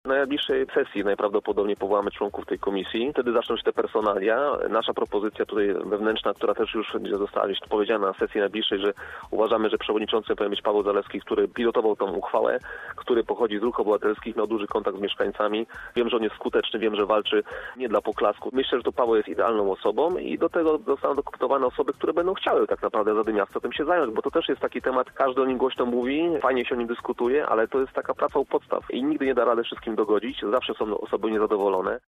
Jak mówi Filip Gryko, przewodniczący klubu Zielona Razem, nowa komisja została powołana ze względu na przyjęcie uchwały dotyczącej regulaminu przeprowadzania konsultacji społecznych z mieszkańcami: